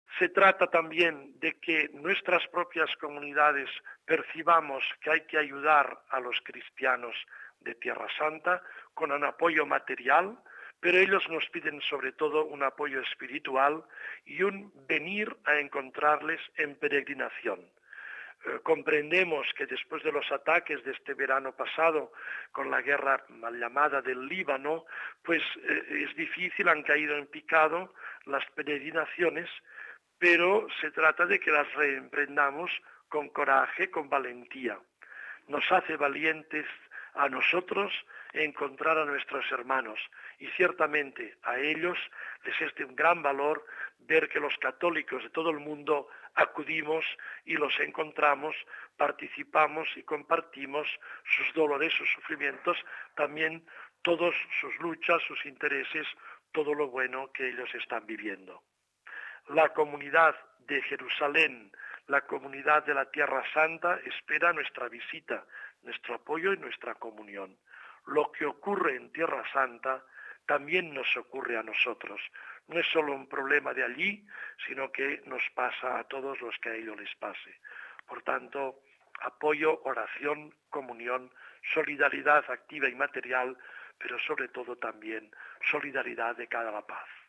Hemos entrevistado a Mons. Joan Enric Vives, Obispo de la Seo d’Urgell, que participa en este encuentro, en representación de la Conferencia Episcopal Española, y comenzamos hablando, de los obispos que han llegado a Tierra Santa, en representación de qué países y Conferencias episcopales.